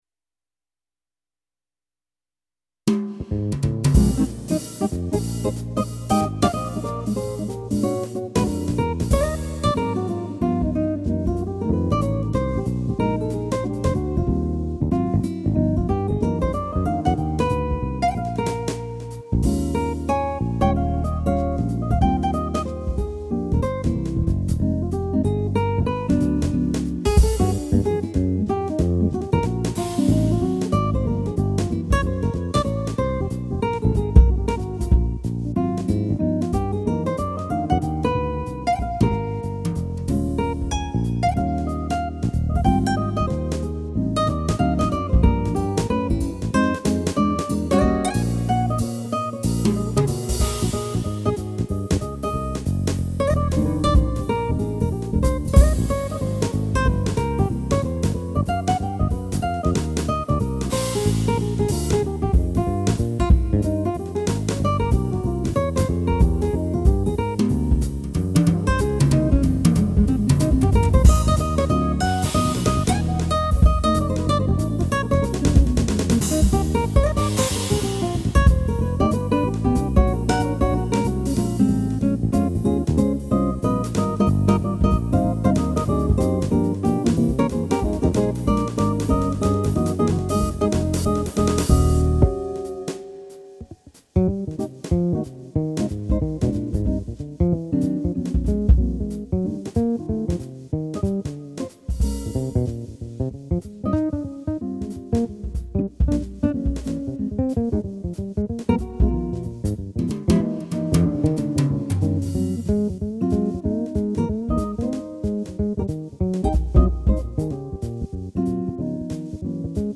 >Êîðî÷å, âîò ïåðâàÿ ïîïàâøàÿñÿ äåìêà Motif XS: